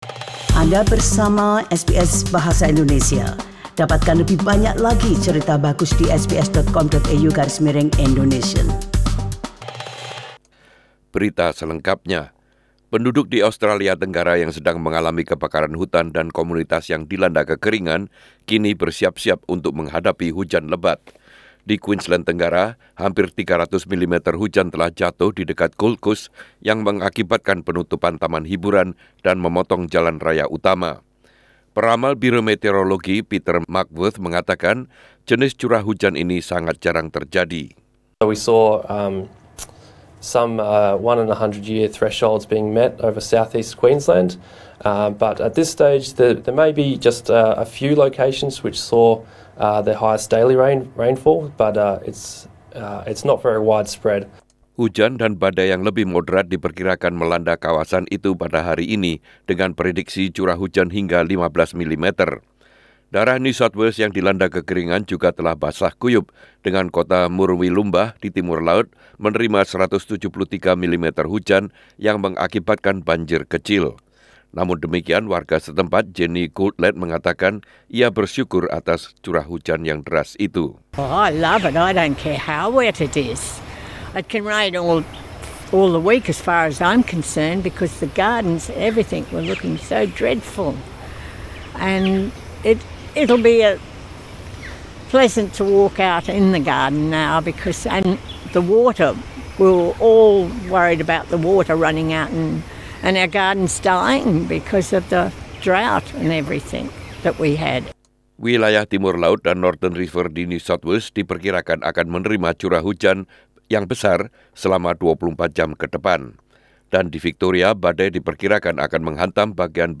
SBS Radio News in Indonesian - 19 Januari 2020